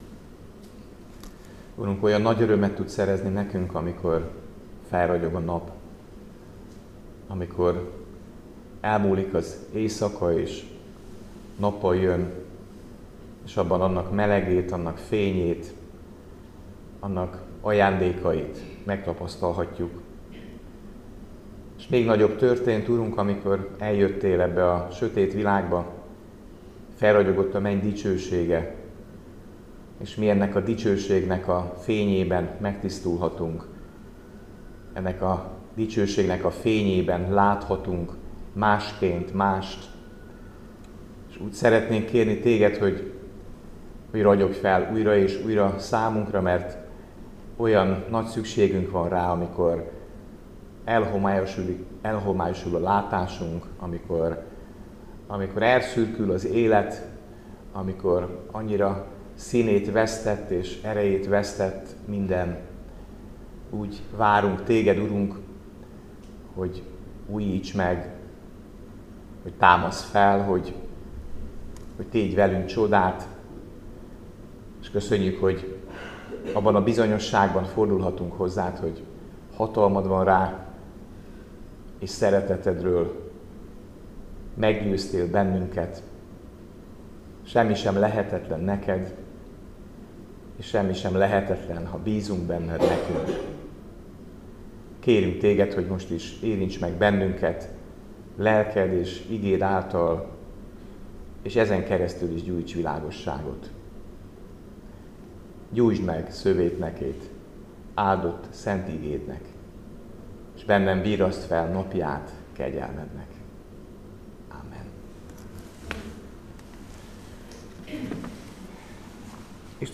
Áhítat, 2024. december 10.